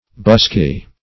Busky \Bus"ky\, a.